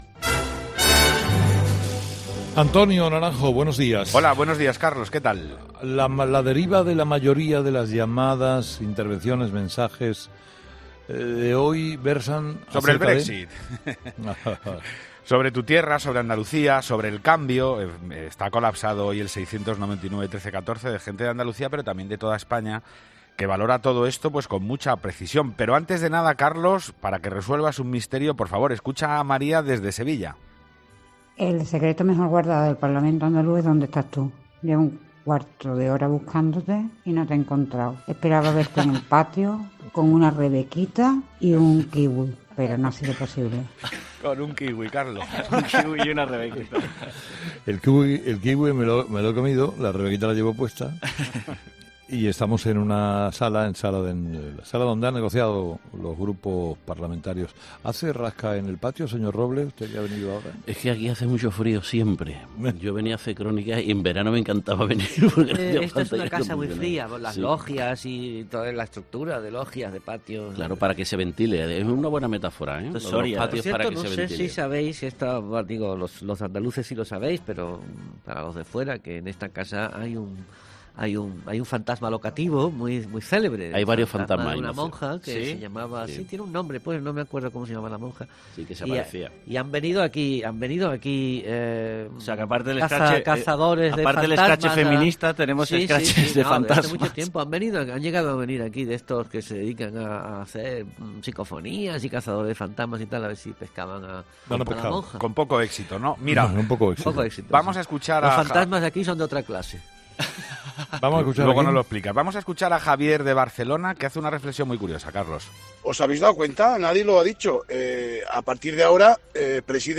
La tertulia de los oyentes es el espacio que Carlos Herrera cede a sus seguidores para que propongan los temas que más les preocupan.